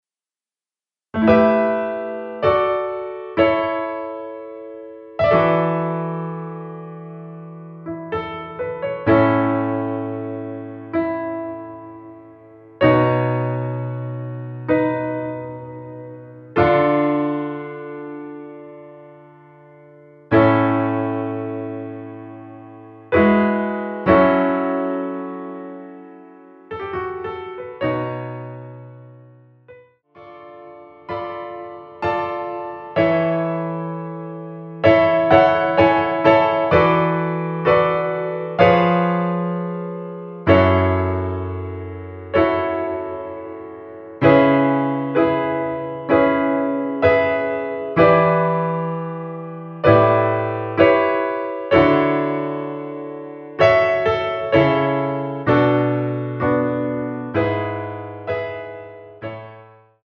피아노만으로 편곡된 MR 입니다.(미리듣기 참조)
앞부분30초, 뒷부분30초씩 편집해서 올려 드리고 있습니다.